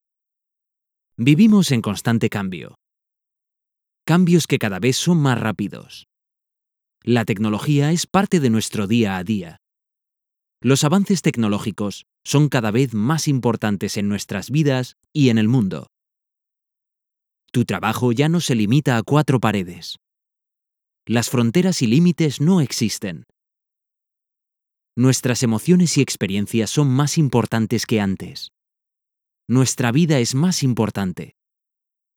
Conversational, versatile, friendly and fresh voice.
EU Spanish
I have my own home recording studio with vocal booth and a large Neumann condenser microphone to provide the highest quality of sound possible.